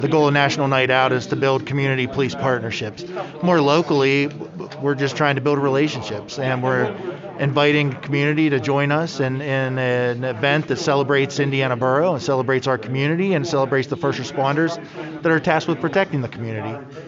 At Tuesday’s Indiana Borough Council planning session, council received their mid-year report from Police Chief Justin Schawl.